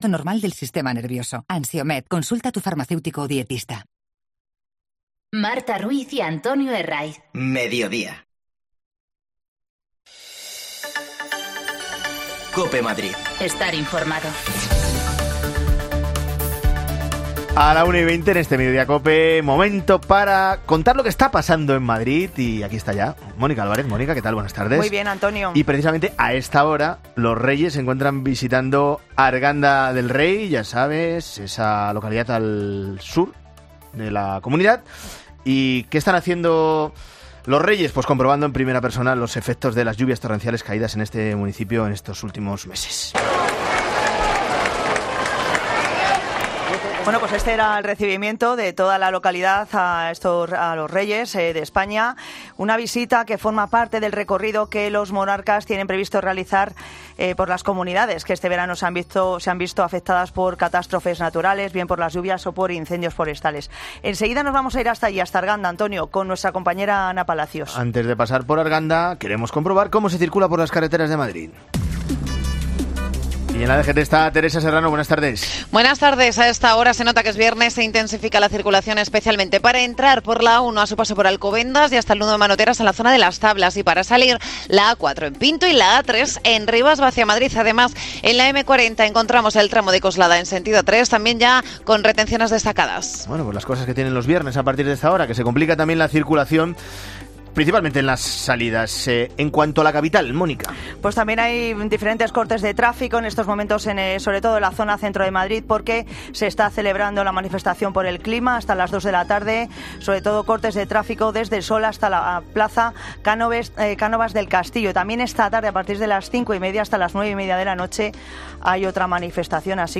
AUDIO: Los Reyes de España visitan Arganda del Rey después de las riadas. Escucha a sus vecinos.